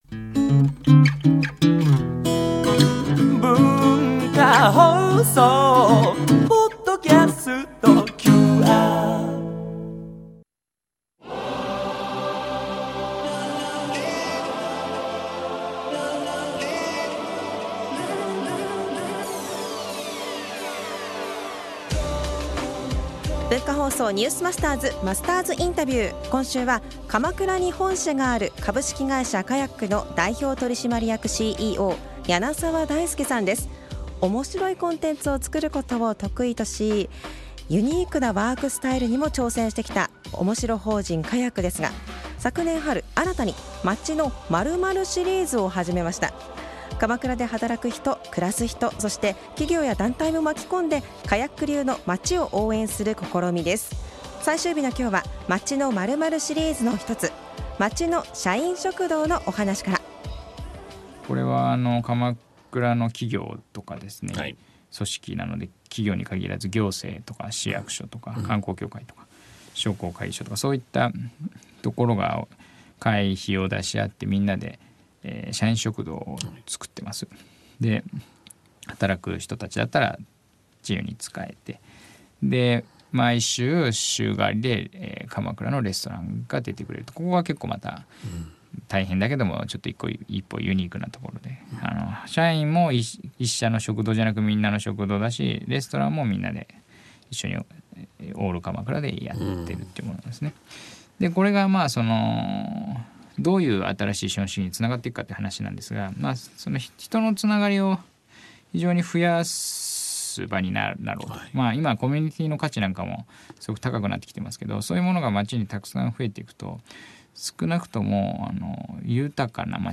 毎週、現代の日本を牽引するビジネスリーダーの方々から次世代につながる様々なエピソードを伺っているマスターズインタビュー。